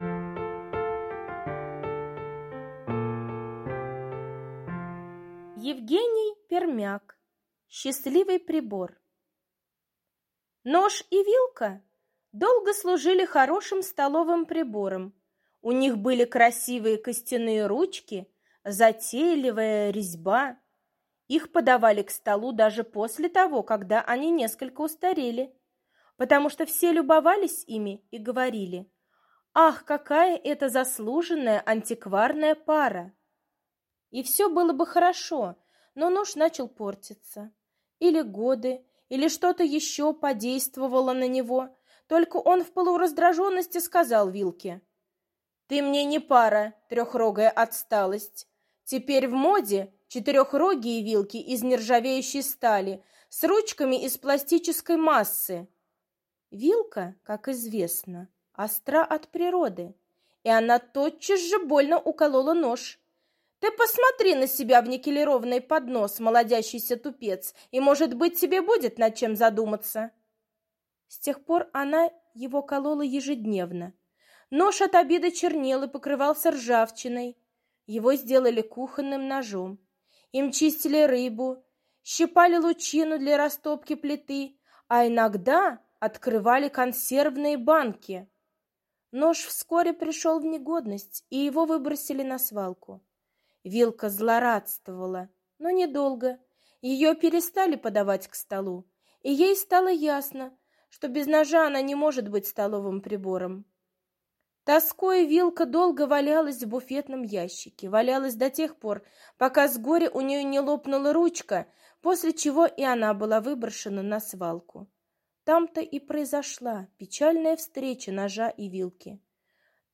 Аудиосказка «Счастливый прибор»